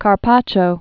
(kär-pächō)